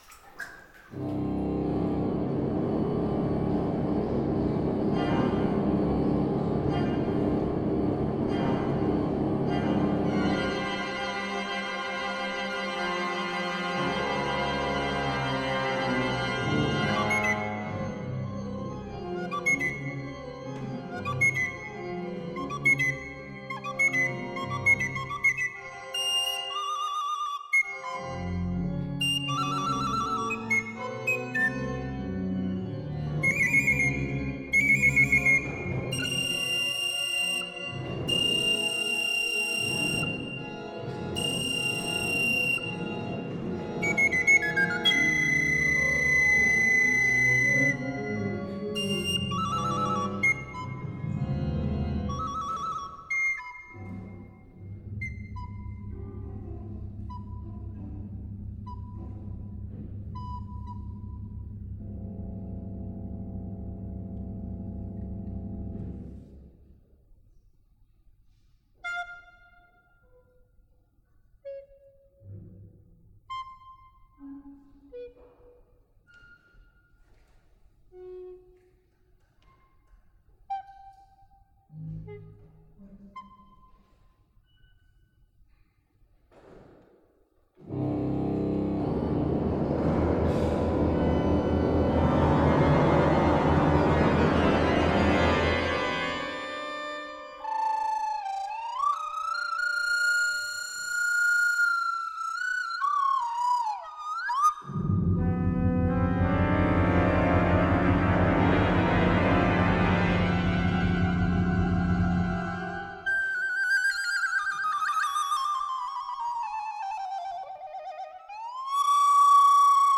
für verschiedene Blockflöten und Orgel